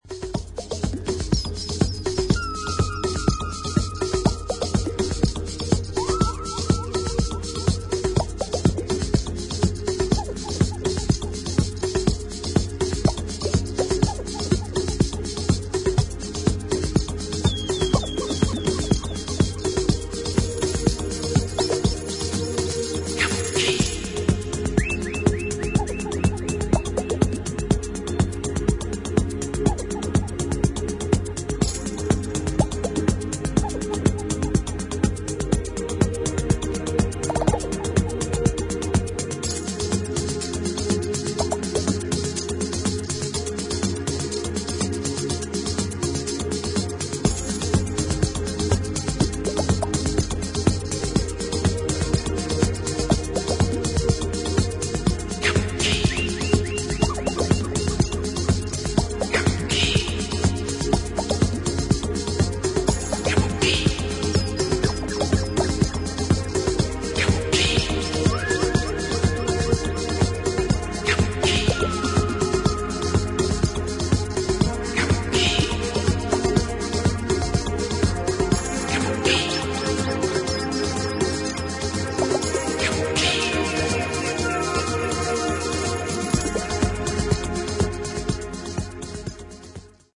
同じく土着的なグルーブに温かみのあるシンセ・ワークでビルドアップする